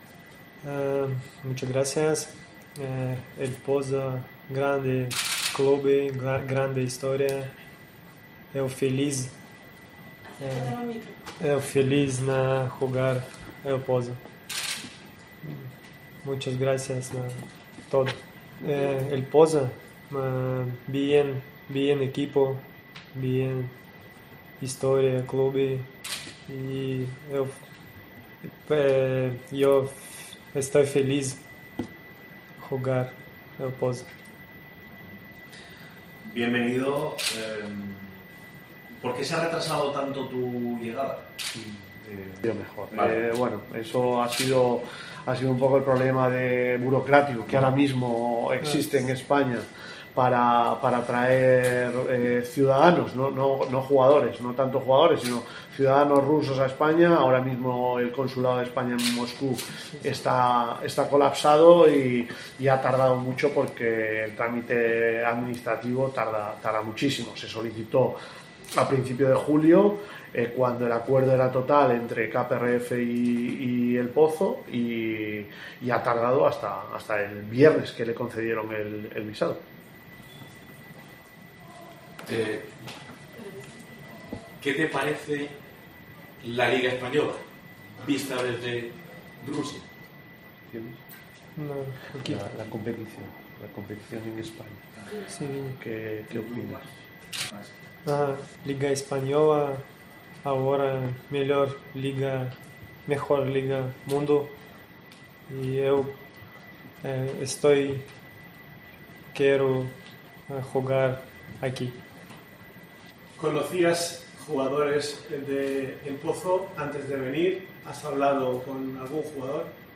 El jugador ruso, que ha dado su rueda de prensa en castellano, indica que "en mi ciudad llegamos a estar a -45 grados"·